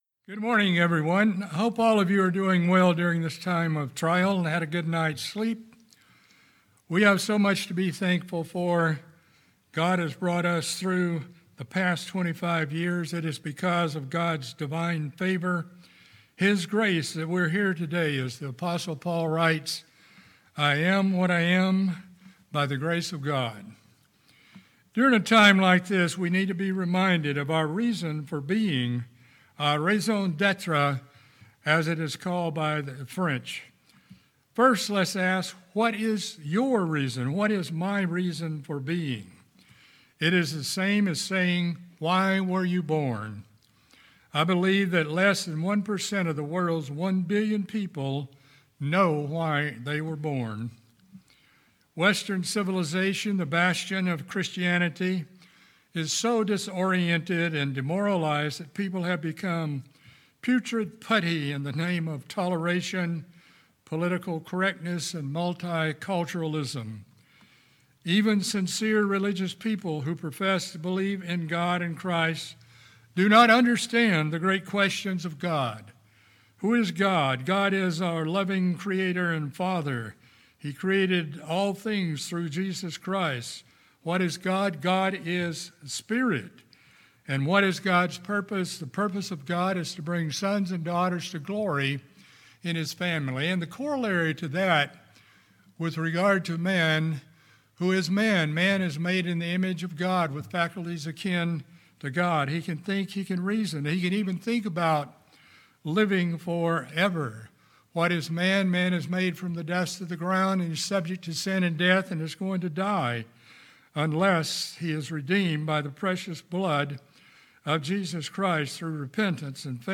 This sermon addresses the Church's reason for being in preaching and teaching the truth of God and preserving the truth that God through Christ revealed to the Church of God. The Church must not lose its identity and thus must proclaim the precious truths that sets it apart as the Church of God as revealed in scripture and summarized to a large degree by the seven great questions of life.